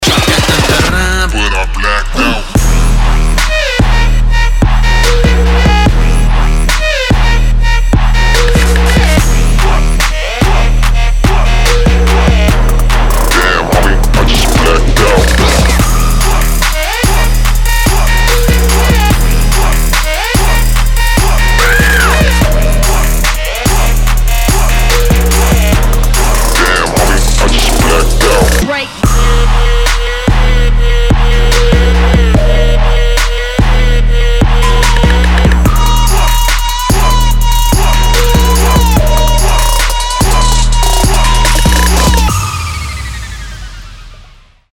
• Качество: 320, Stereo
Тамтарам))) Жесткий Трэпчик!